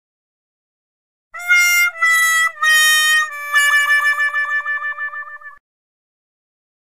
Wah Wah Wahhhh